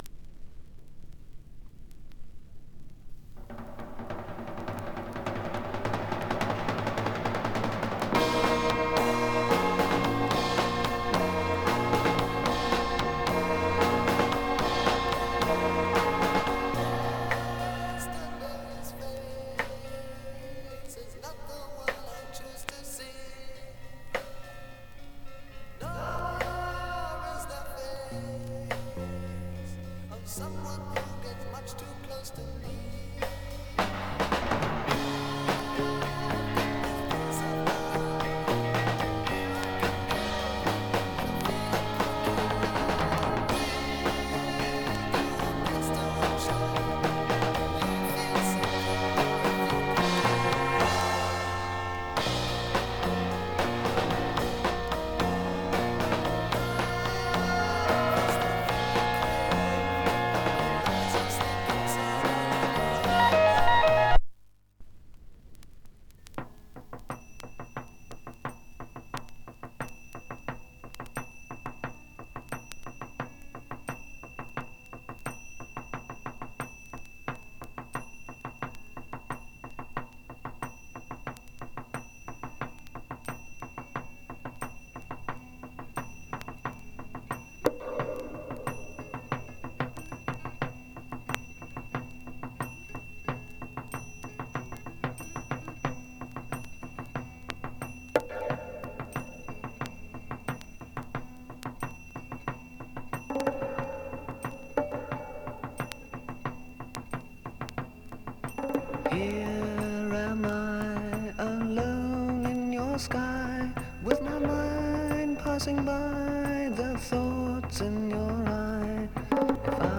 Жанр: PSYCH